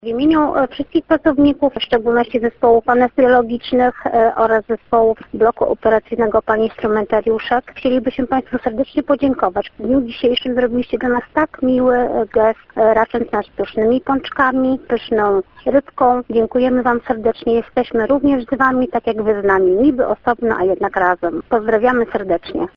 Mówiła w imieniu białego personelu ełckiego szpitala wojskowego jedna z pracownic.